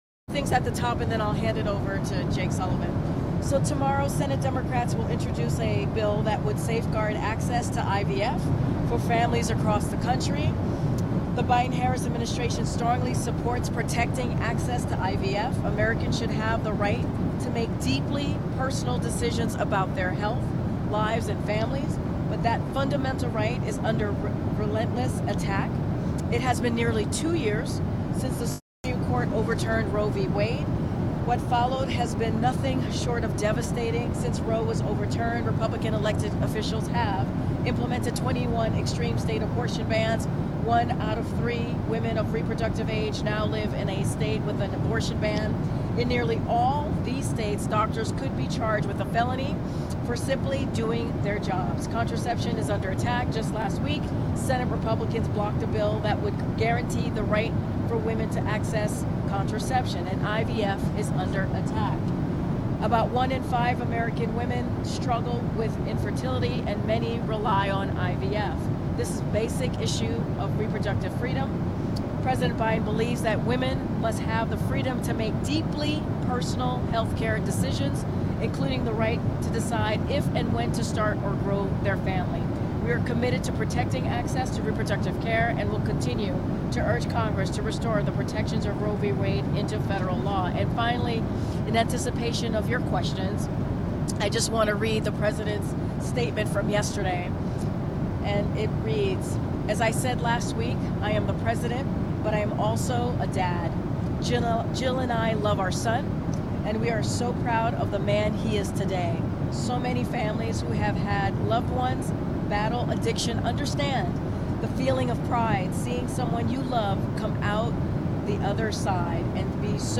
Press Secretary Karine Jean-Pierre and Jake Sullivan Gaggle Aboard Air Force One White House Communications Agency